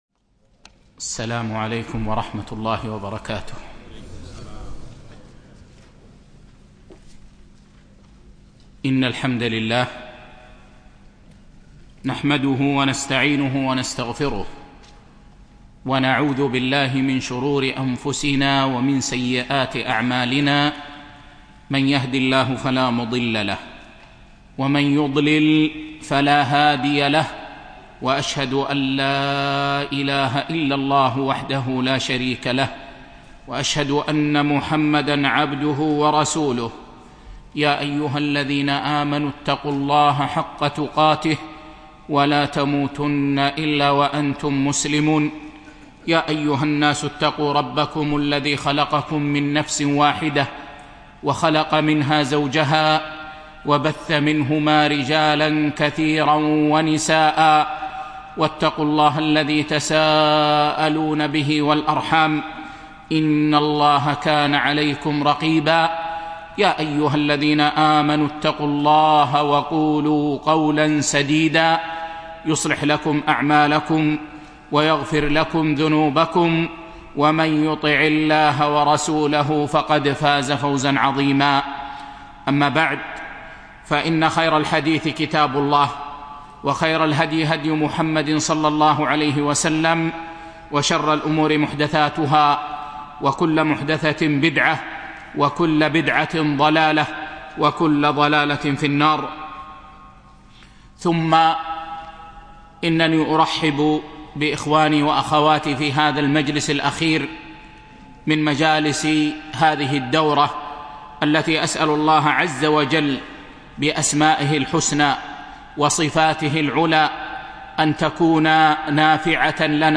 10- فقه المعاملات المالية المعاصرة (3) - الدرس السابع